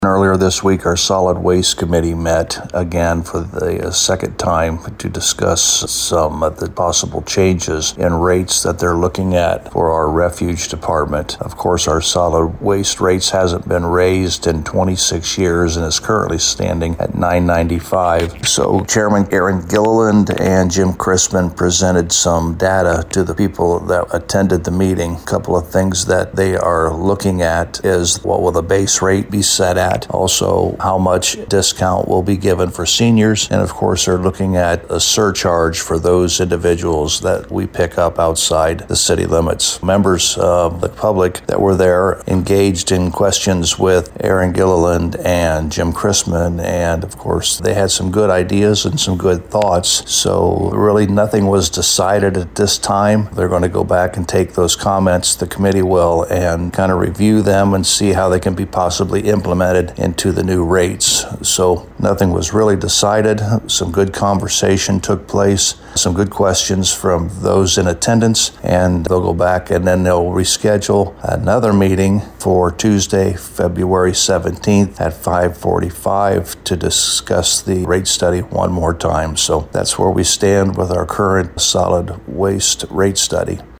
To hear Mayor Hurlburt's Report: